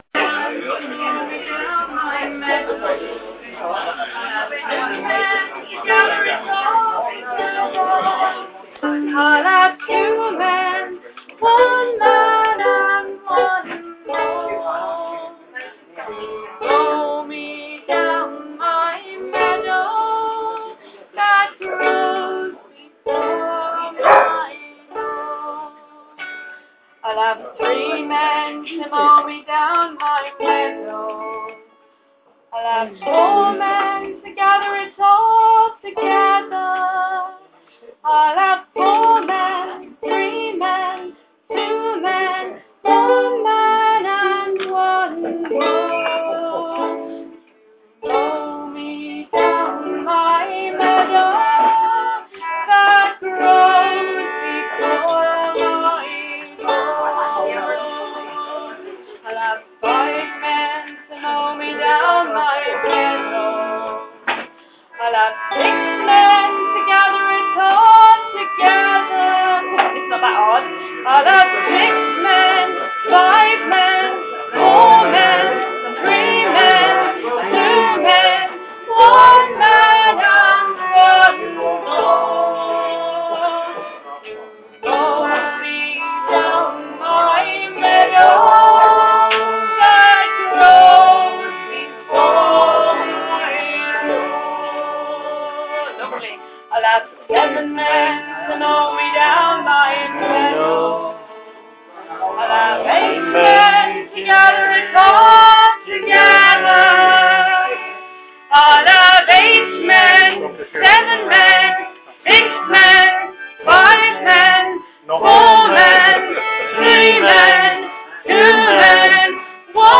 Meadow Song. From folk session at Lamb and Flag after Worcester Uke Club, hence the uke accompaniment.